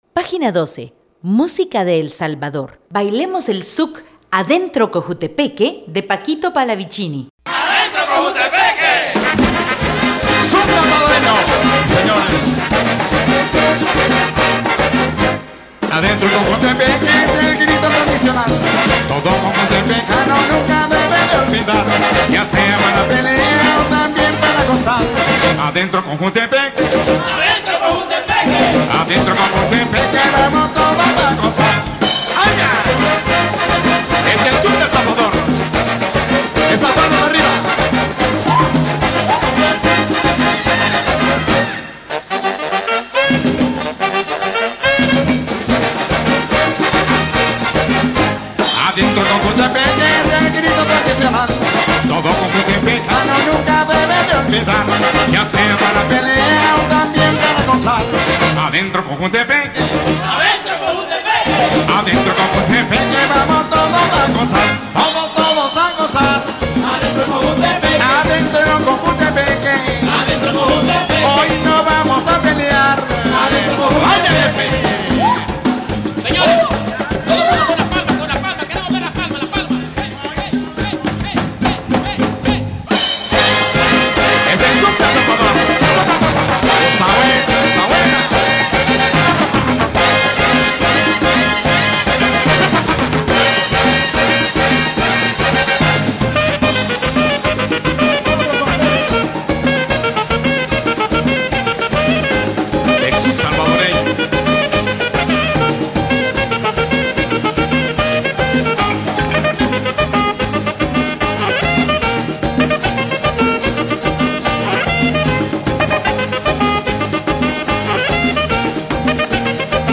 Xuc